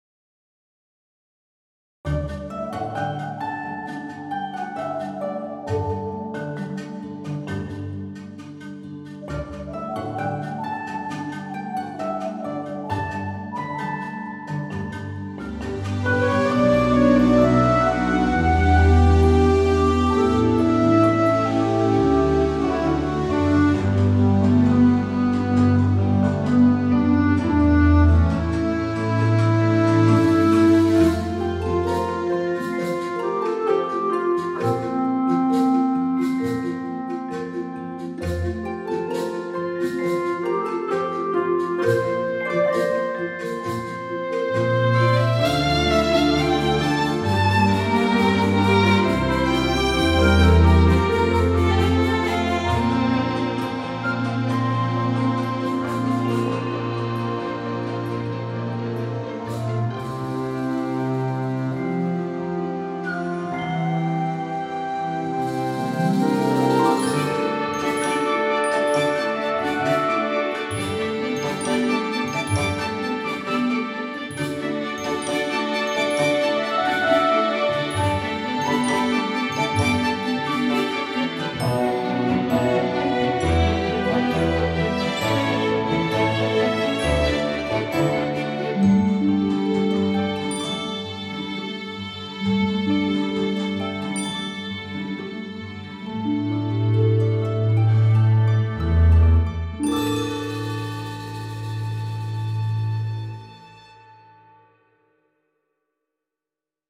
Yesterday’s material gets another rendition in today’s music which is music for casual dialogue.